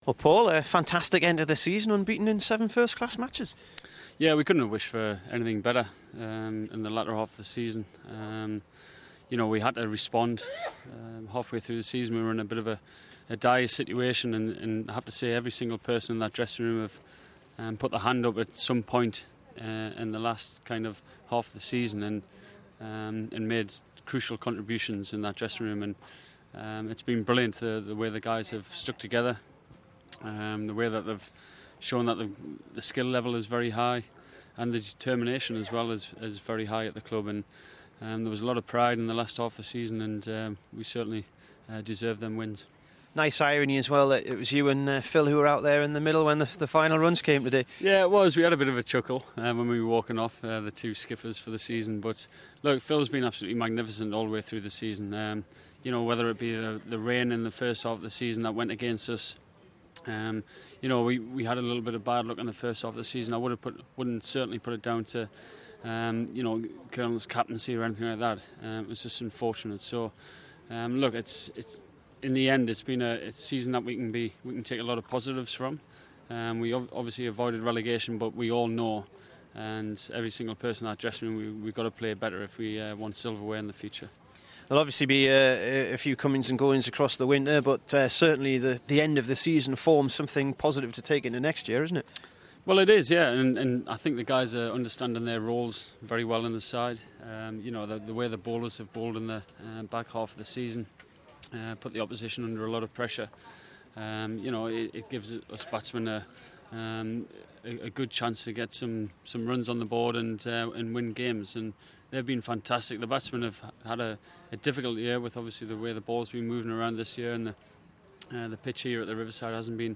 PAUL COLLINGWOOD INT